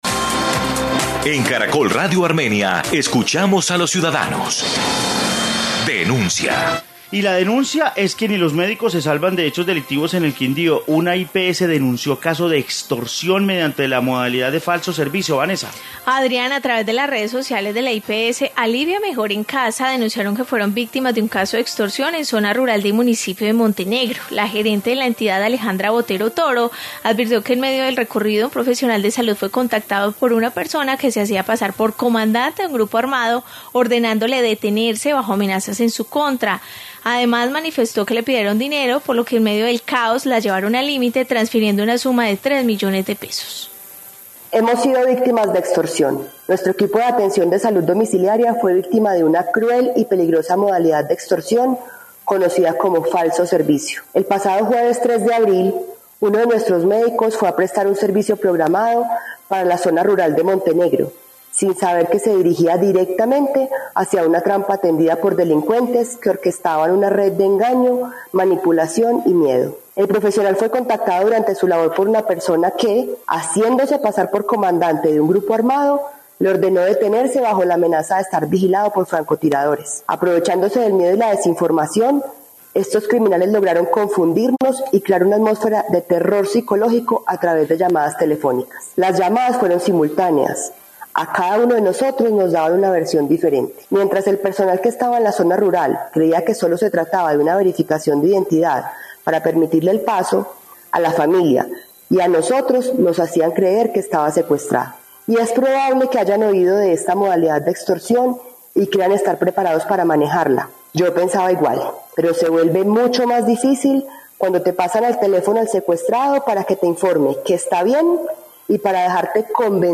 Informe caso de extorsión en el Quindío